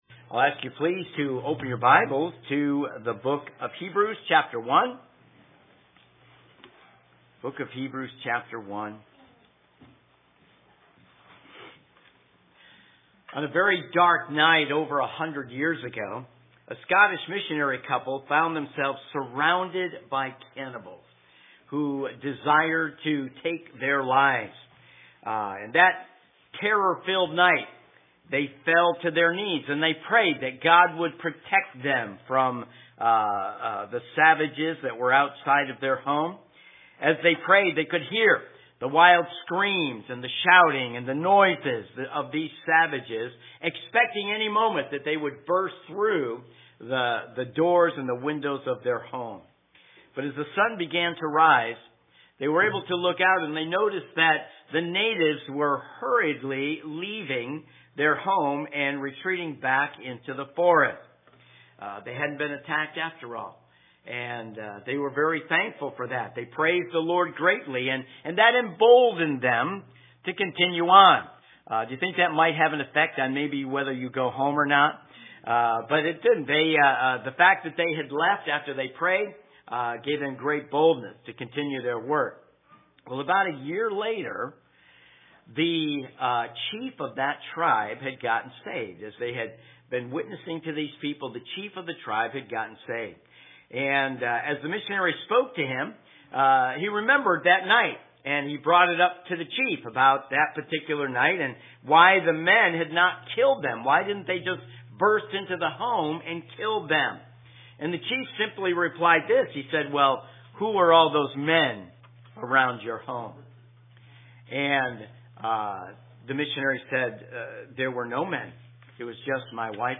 AM Messages